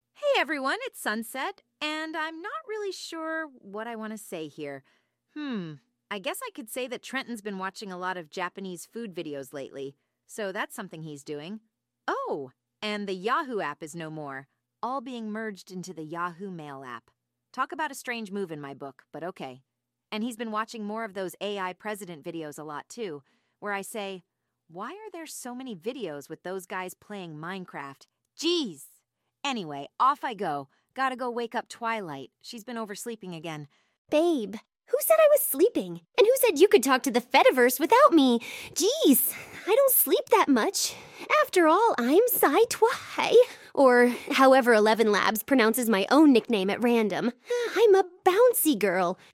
As for adding sound effects? That's better to be done on a Computer, but hey, hope y'all enjoy anyways!